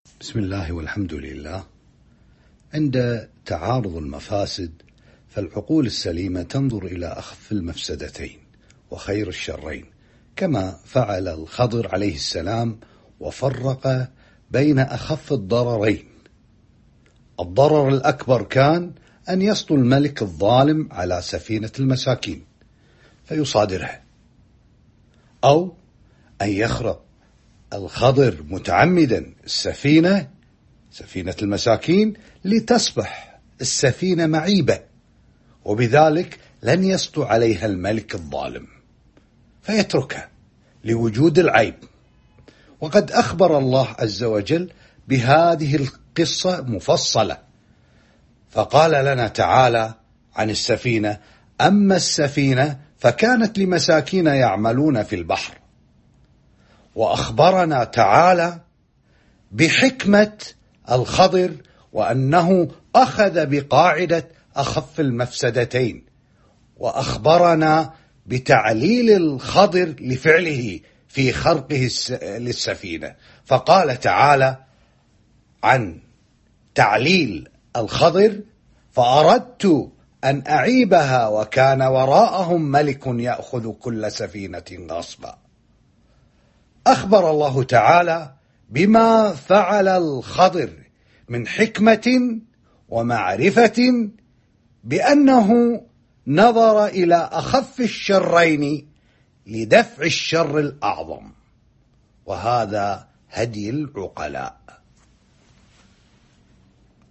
Format: MP3 Mono 32kHz 32Kbps (ABR)